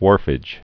(wôrfĭj, hwôr-)